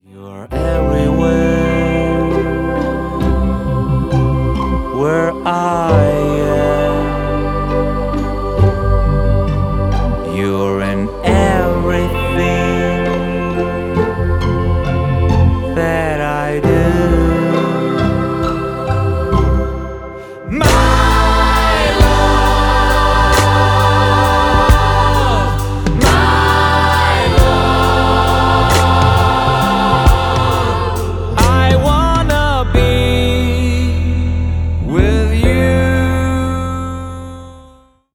• Качество: 320 kbps, Stereo
Поп Музыка
спокойные